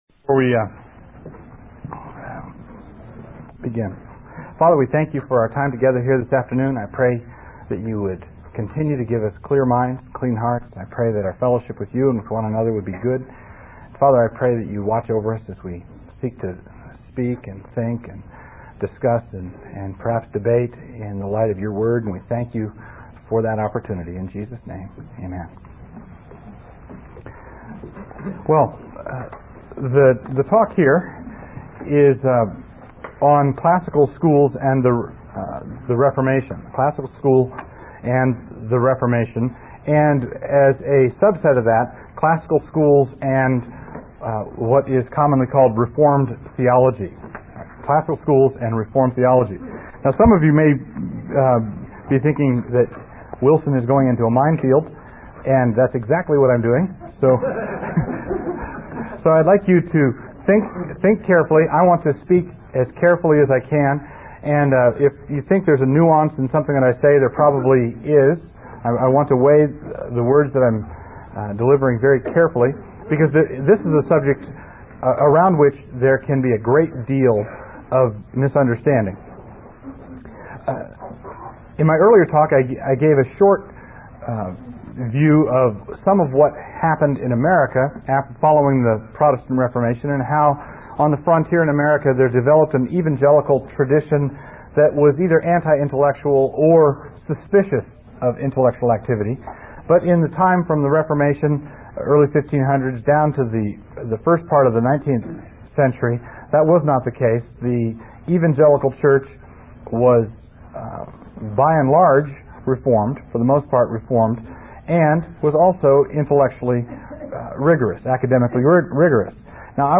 1995 Practicum Talk | 0:44:43 | All Grade Levels, Culture & Faith
Additional Materials The Association of Classical & Christian Schools presents Repairing the Ruins, the ACCS annual conference, copyright ACCS.